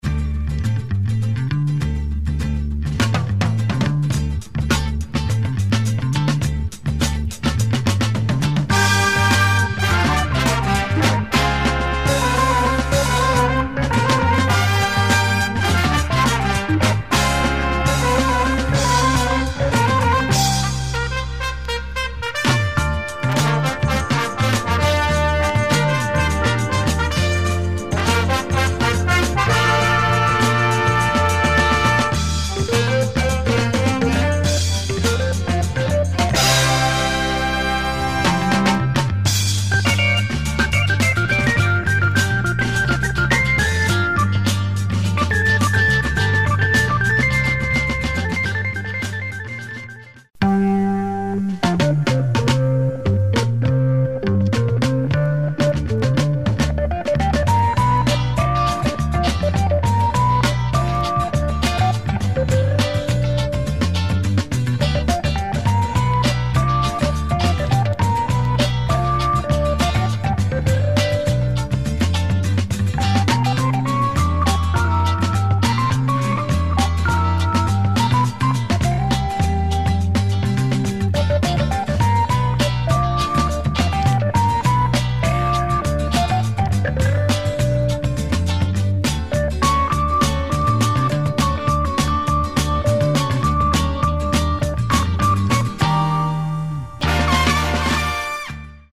French soundtracks
organ storm
are pop groovy with female chorus